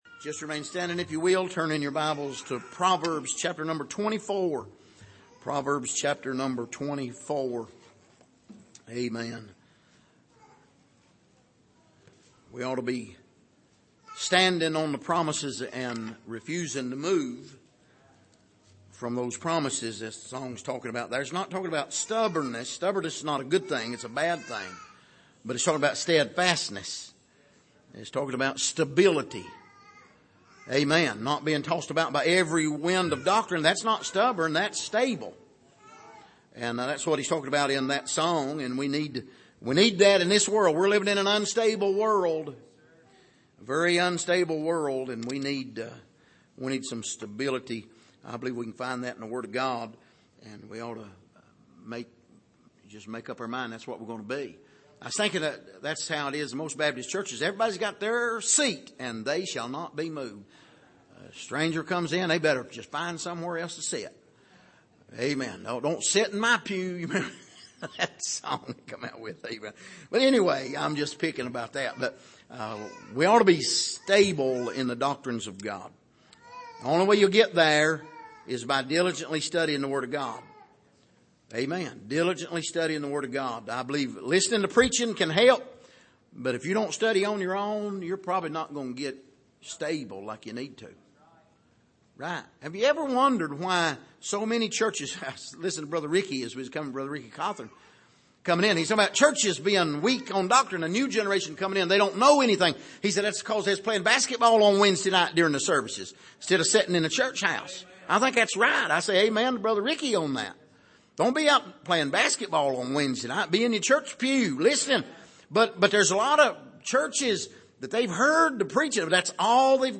Passage: Proverbs 24:1-9 Service: Sunday Evening